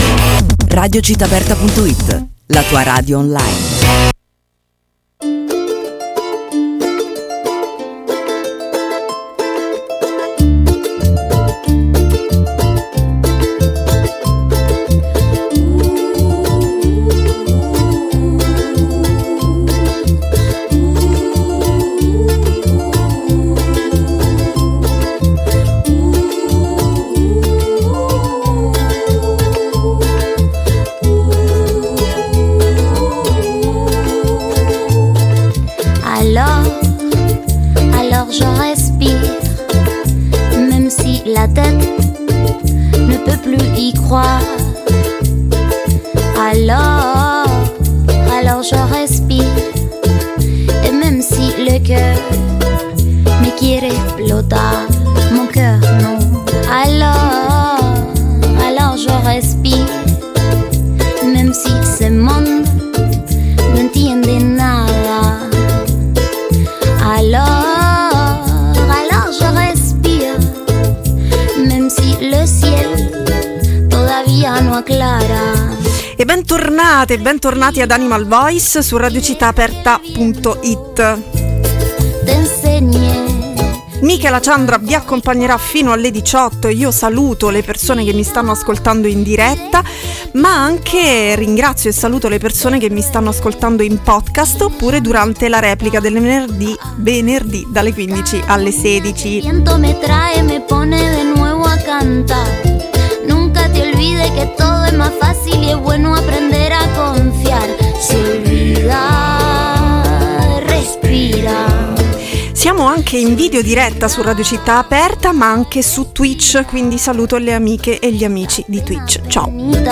Scaletta musicale